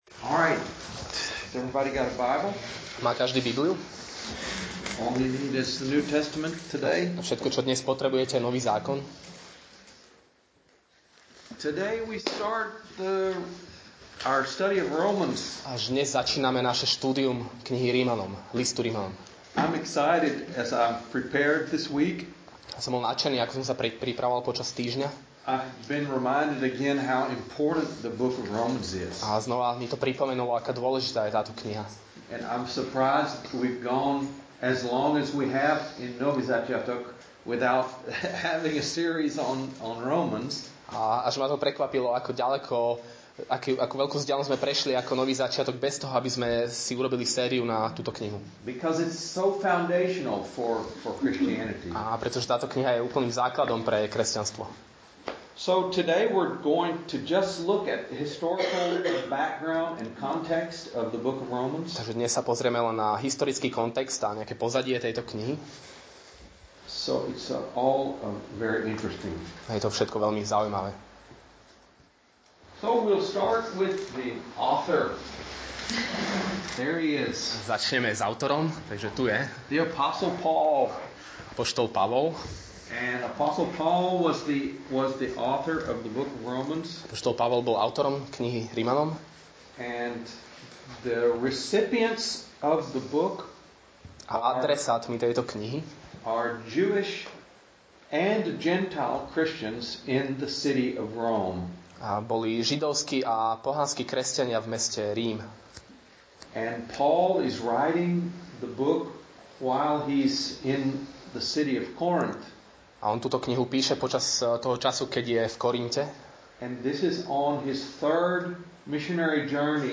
Nahrávka kázne Kresťanského centra Nový začiatok z 5. februára 2017